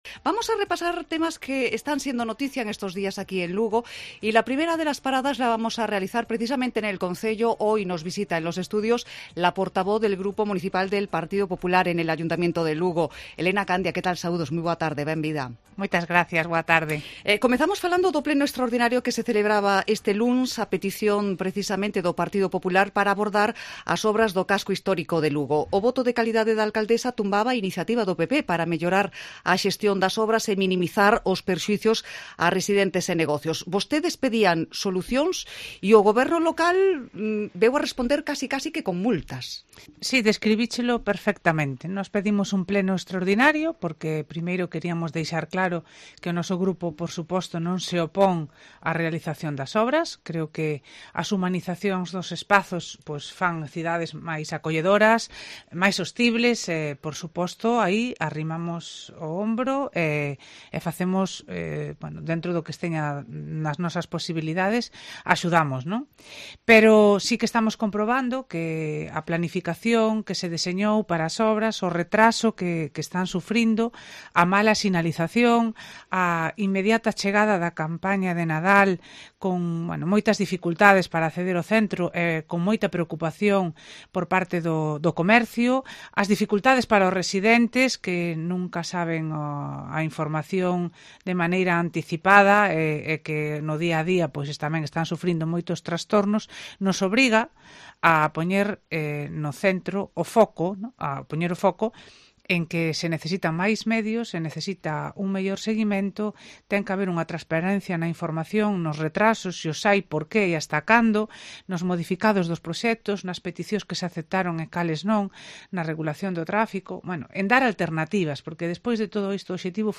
Entrevista a Elena Candia en COPE Lugo